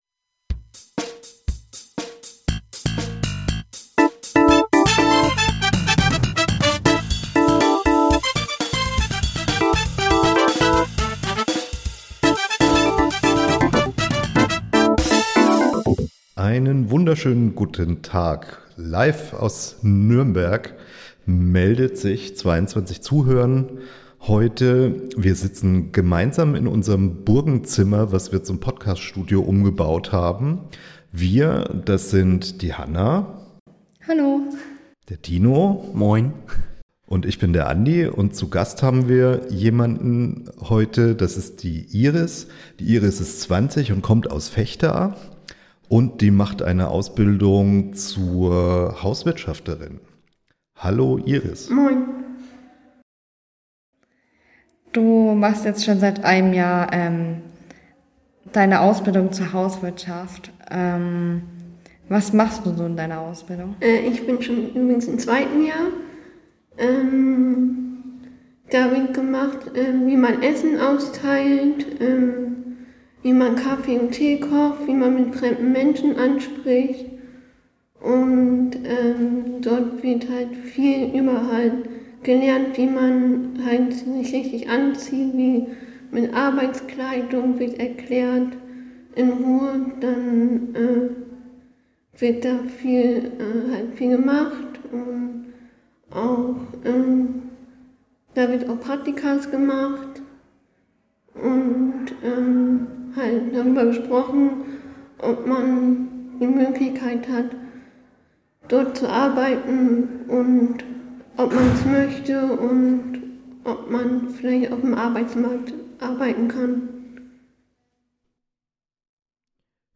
Sie erzählten uns auf den "Thementagen Herbst" in Nürnberg etwas über ihren Berufsalltag.